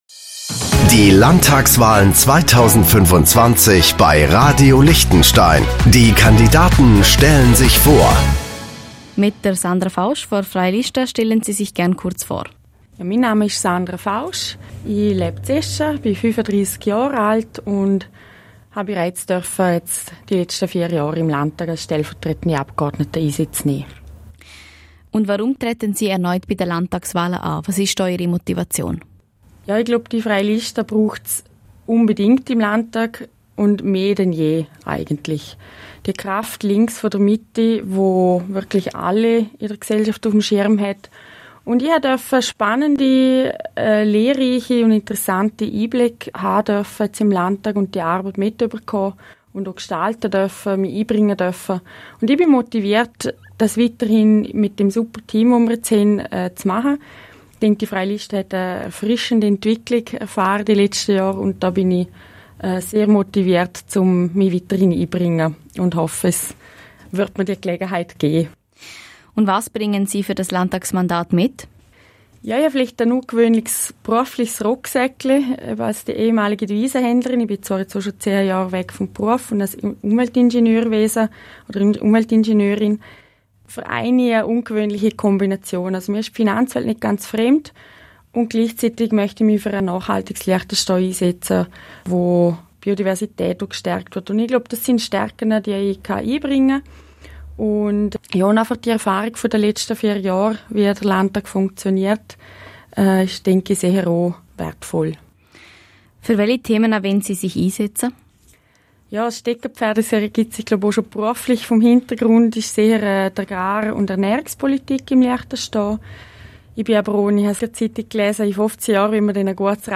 Landtagskandidatin